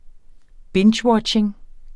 Udtale [ ˈbendɕˌwʌdɕeŋ ]